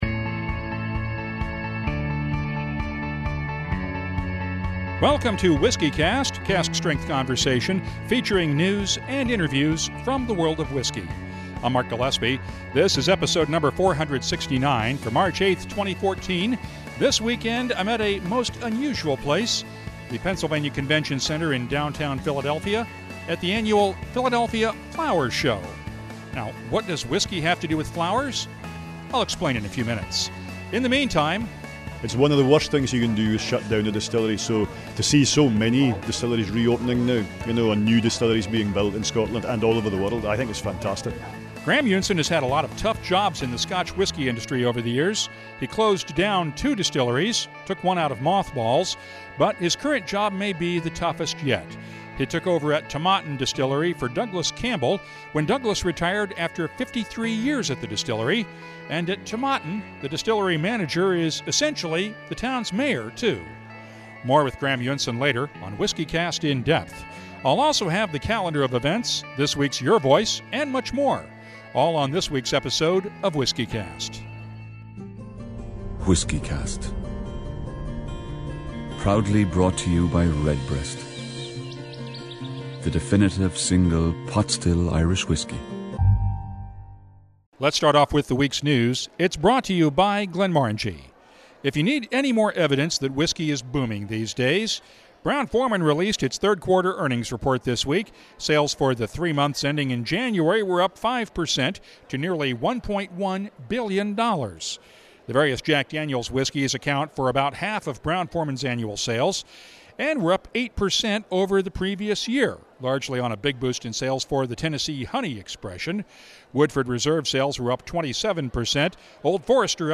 This week’s episode was recorded on location at the Philadelphia Flower Show